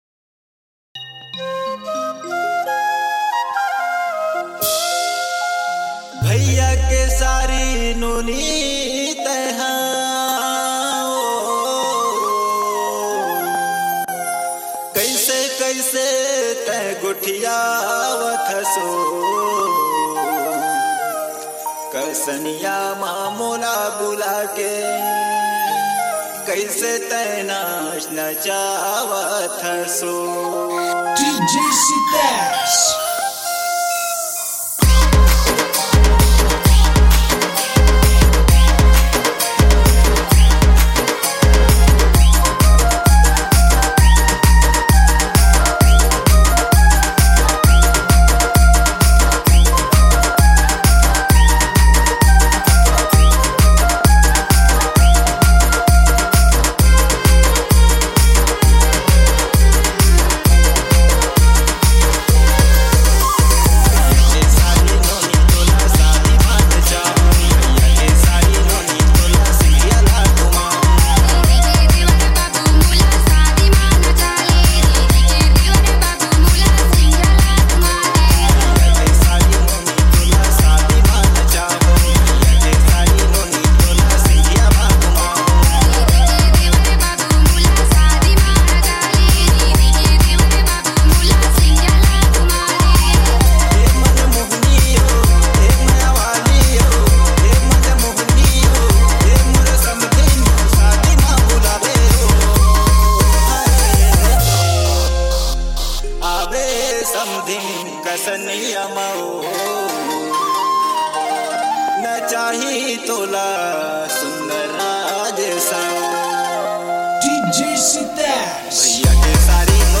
Gold And Old DJ song